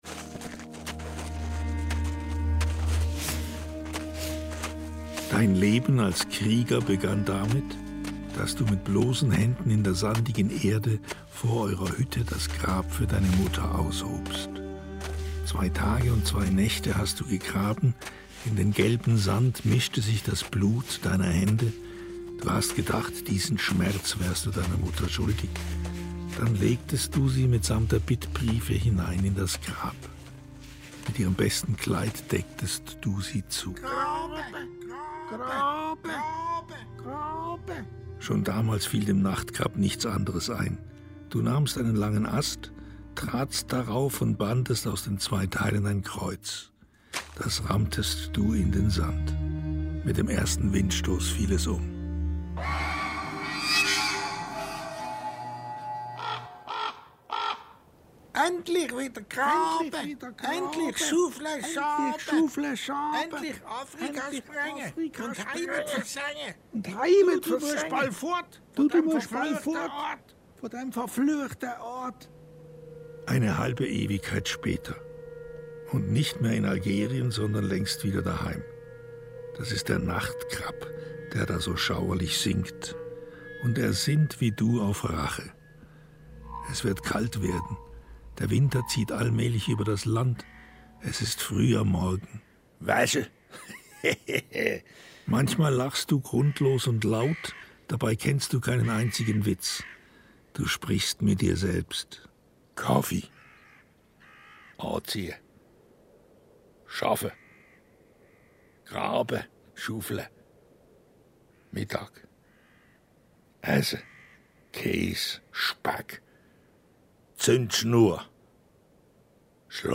Hörspiel des Monats September 2025
Hin und wieder dringen Spott und Tratsch und Heuchelei der Pfaffenweiler zu ihm durch. Aus dem Mosaik seiner Gedanken, Stimmen, Erinnerungen setzt sich allmählich und sehr kunstvoll die Geschichte zusammen.
Die Mundart als Stilmittel ist mutig und herausfordernd.